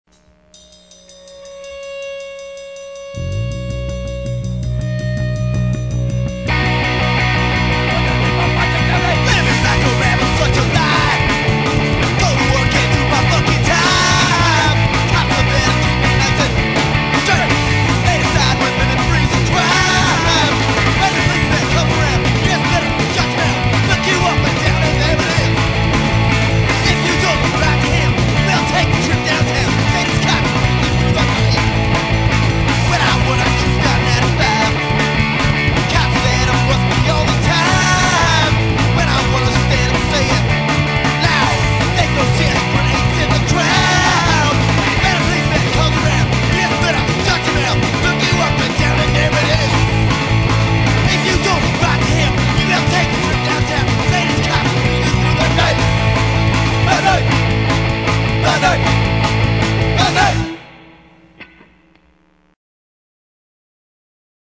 Garage punk band
garage rock See all items with this value
punk rock See all items with this value
vinyl record